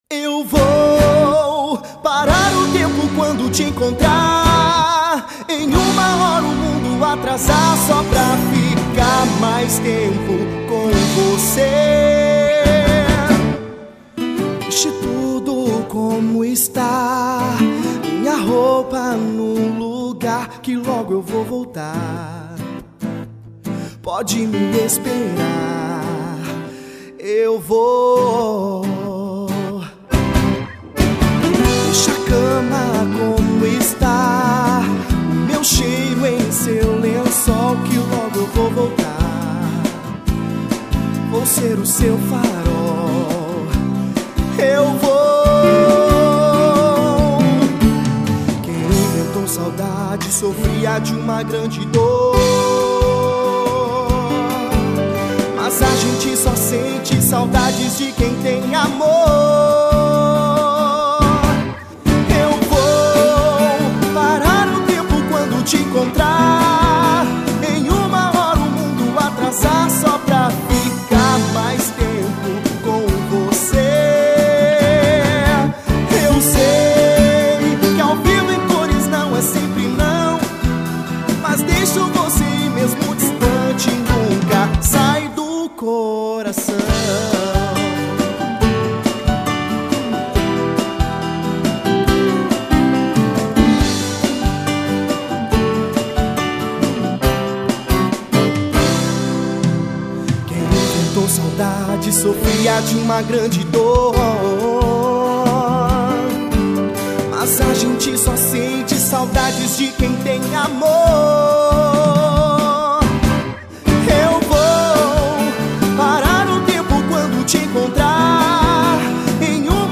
Batidão Gaúcho.